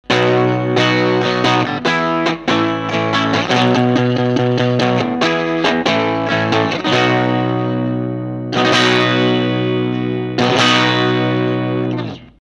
薄くなり、スッキリした印象もありますが